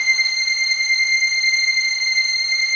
DM PAD3-03.wav